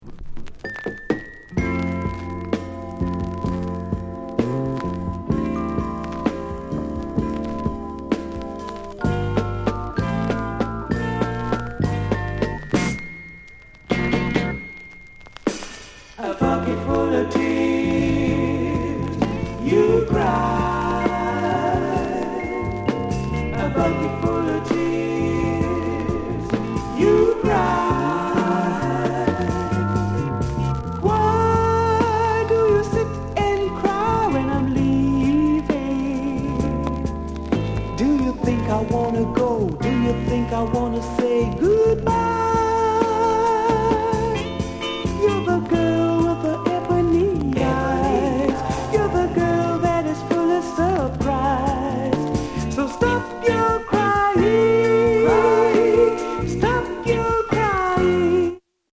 SOUND CONDITION VG(OK)
SOULFUL VOCAL